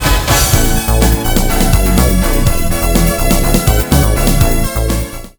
(Lower volume first on your speaker, it's loud!)
I like composing "Sweet, Cute, and Catchy" style music.